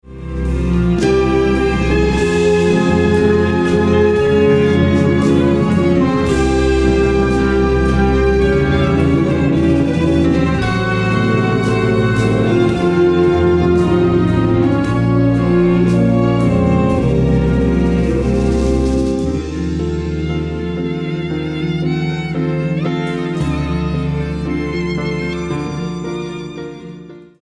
Orchesterfassung